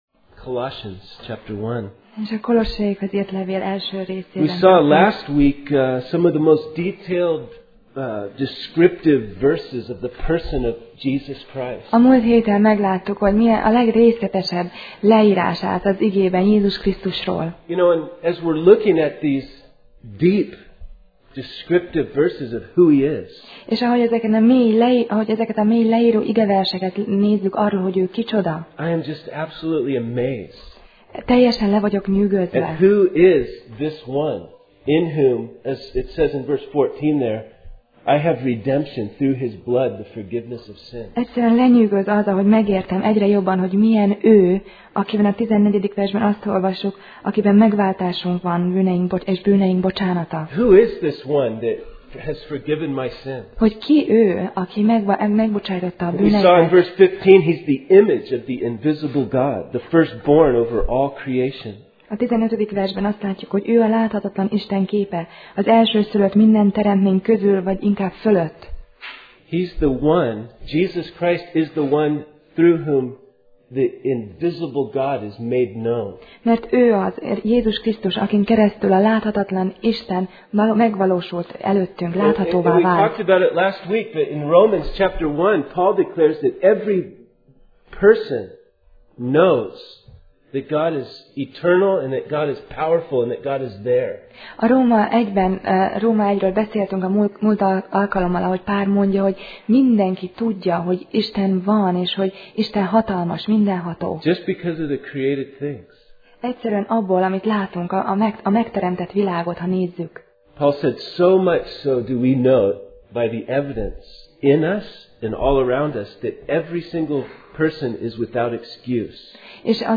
Kolossé Passage: Kolossé (Colossians) 1:18-22 Alkalom: Vasárnap Reggel